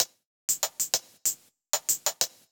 Index of /musicradar/ultimate-hihat-samples/95bpm
UHH_ElectroHatD_95-03.wav